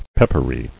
[pep·per·y]